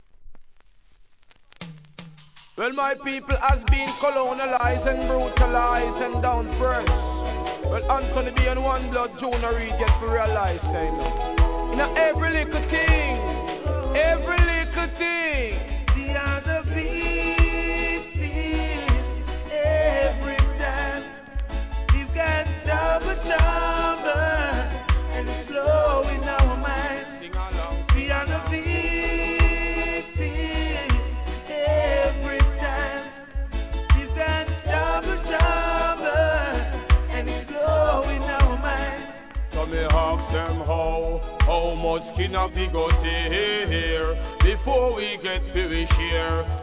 REGGAE
コーラスを交えた好コンビネーション!!!